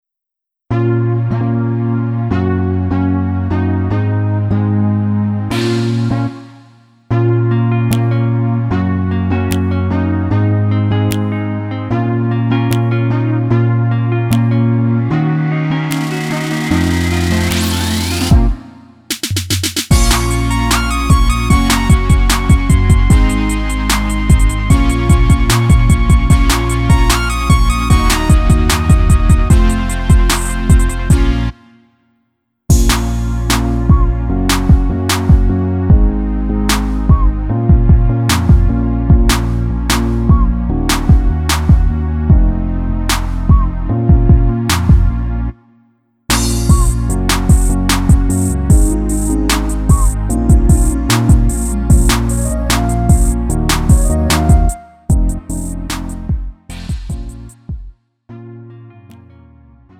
음정 -1키 3:27
장르 구분 Lite MR